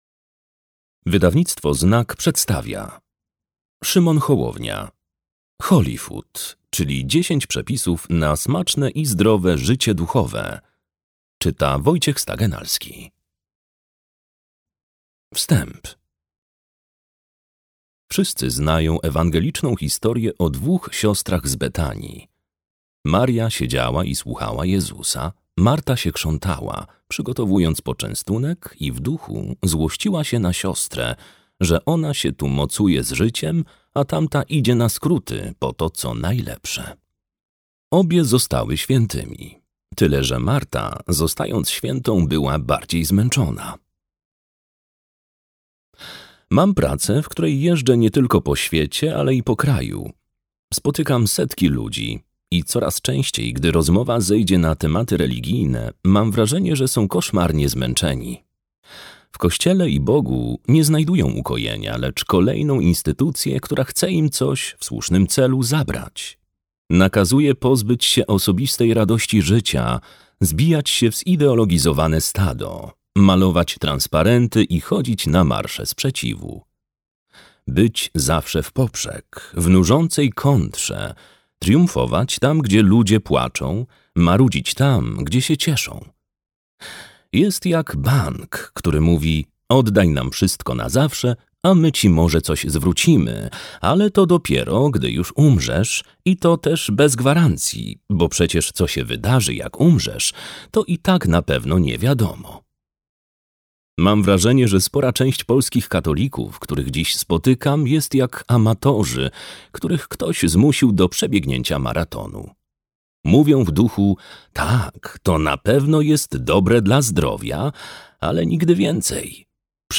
Holyfood, czyli 10 przepisów na smaczne i zdrowe życie duchowe - Szymon Hołownia - audiobook + książka - Legimi online